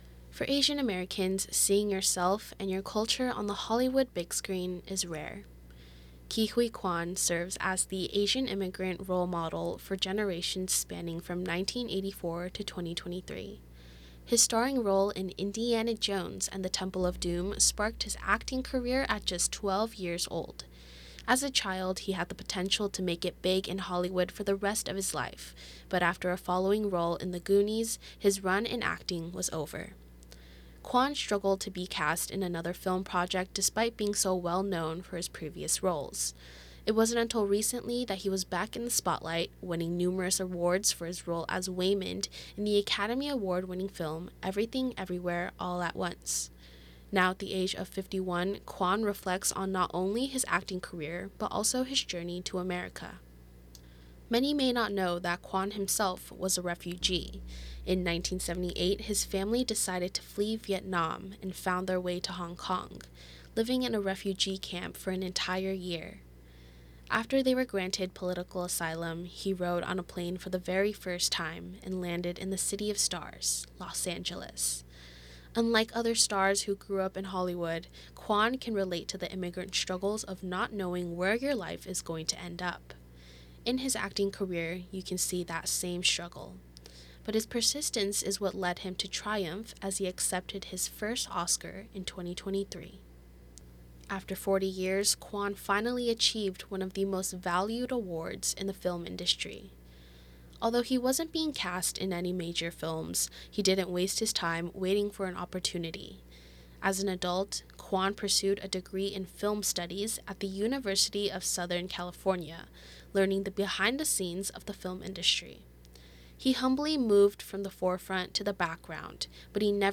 An audio reading of the My Hero story Ke Huy Quan - Immigrant to Award-Winning Actor